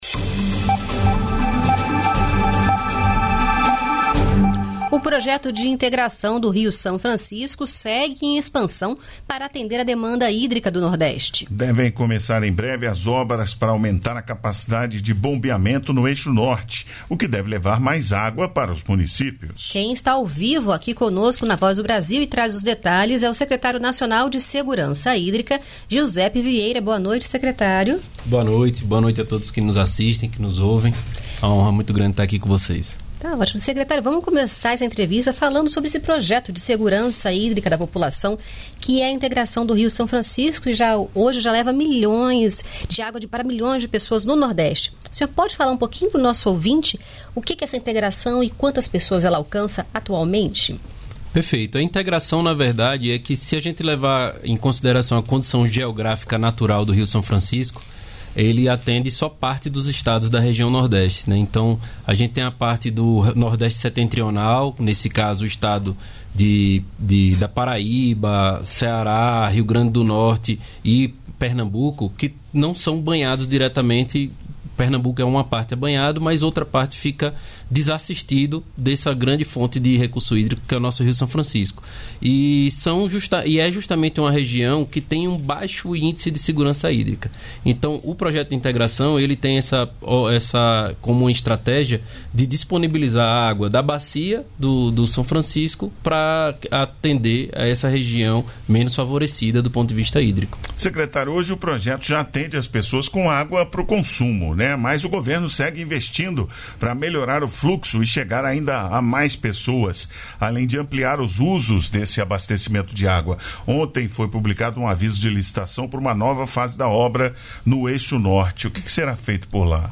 Secretário Nacional de Segurança Hídrica, Giuseppe Vieira